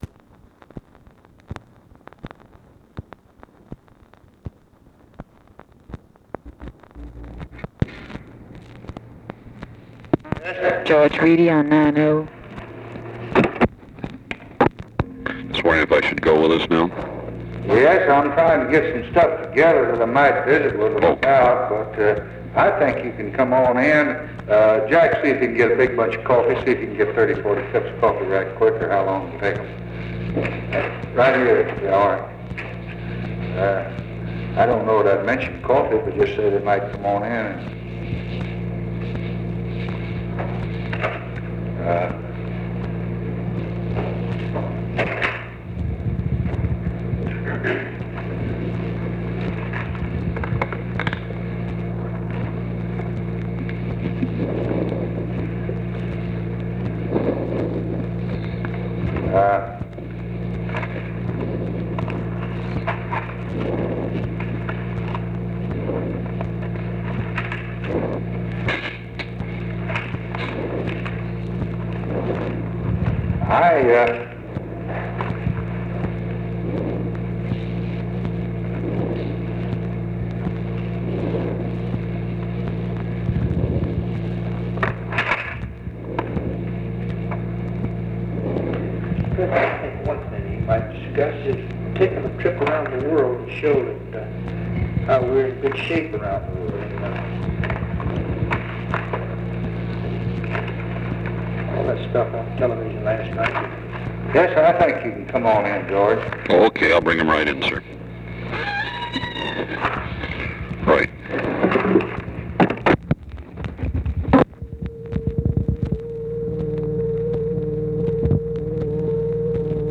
Conversation with GEORGE REEDY and OFFICE CONVERSATION, July 17, 1964
Secret White House Tapes